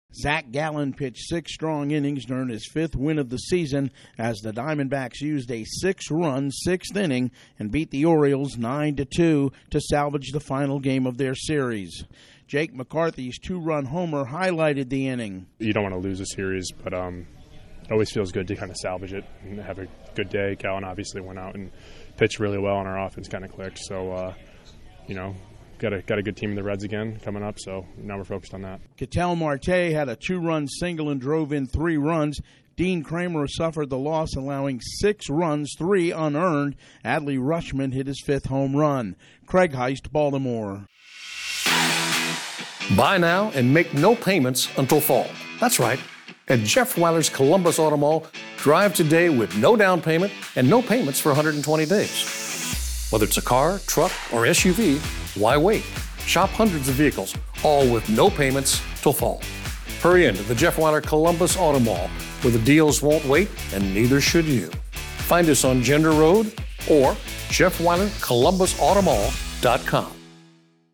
The Diamondbacks hammer the Orioles in the series finale. Correspondent